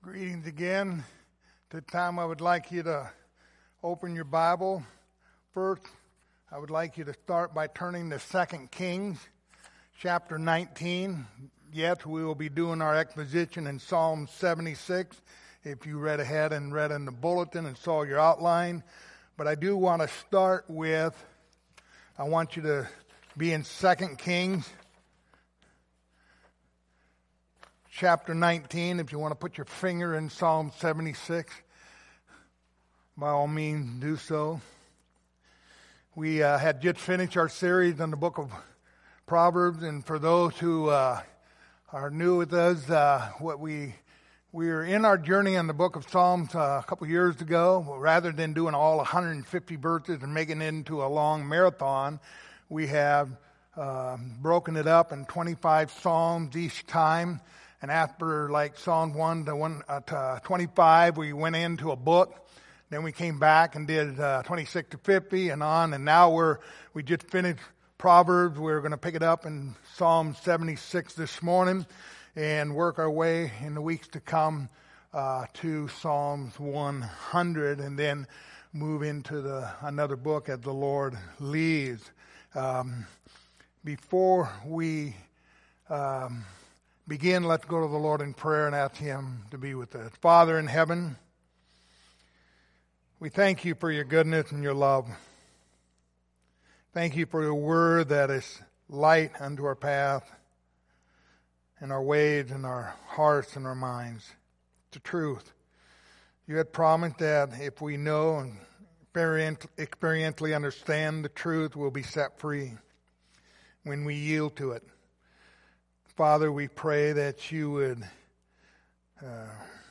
Passage: Psalms 76 Service Type: Sunday Morning